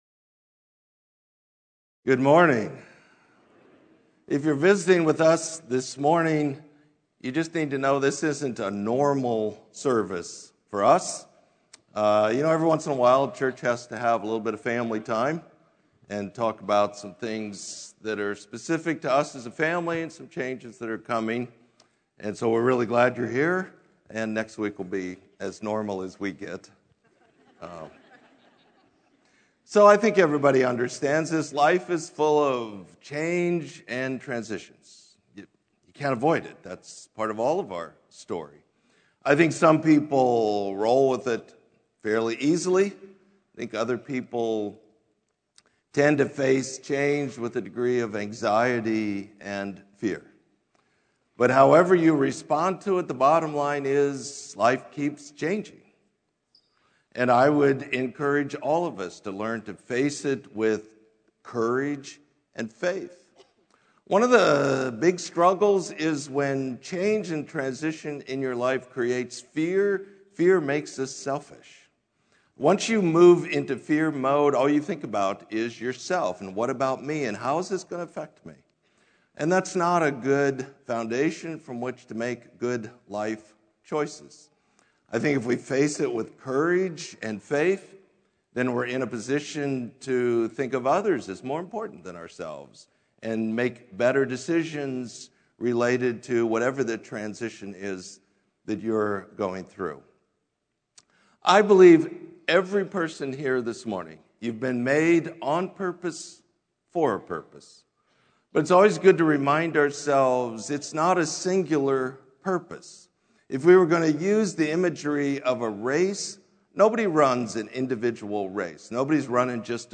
Sermon: Passing the Baton